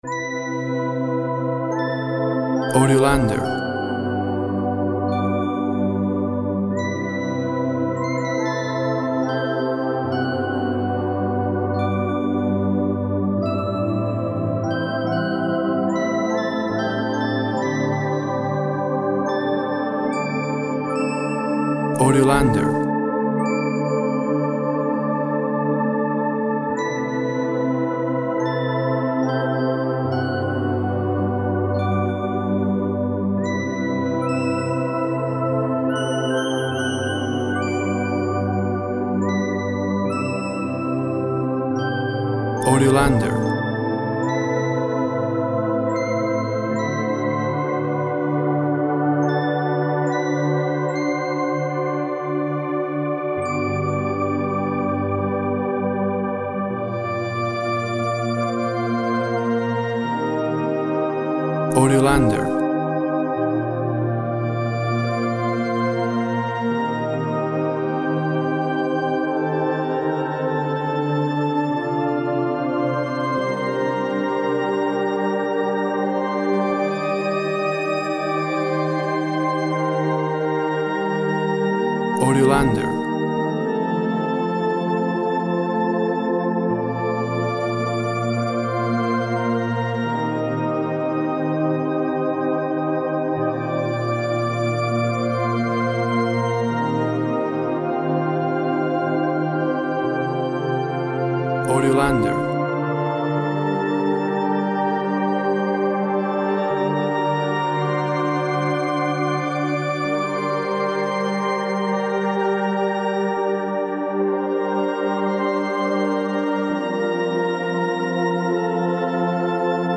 Melancholy dense synth sounds.
Tempo (BPM) 72